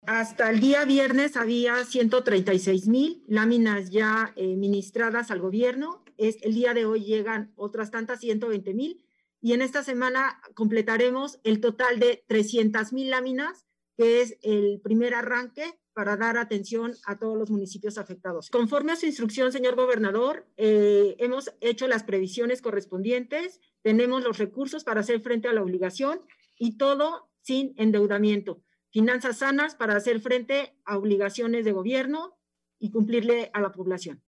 En la habitual videoconferencia de prensa, estimó que las afectaciones que generó este meteoro en viviendas, escuelas y unidades médicas estarán rehabilitadas en un periodo de dos meses, mientras que la limpieza de caminos estará concluida en dos semanas, luego de recalcar que no hay comunidades incomunicadas en Puebla.